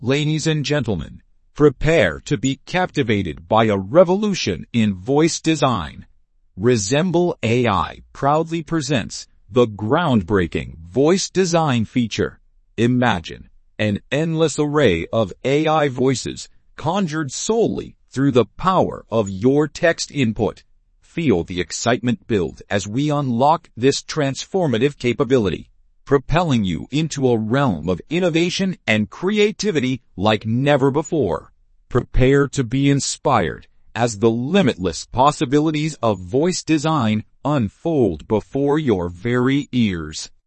Example 2: Narrator Styles
A super deep male voice announcer with a dramatic performance that is unveiling Resemble AI's new Voice Design feature